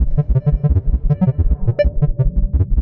Index of /musicradar/rhythmic-inspiration-samples/85bpm
RI_ArpegiFex_85-02.wav